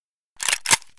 mp133_pump.ogg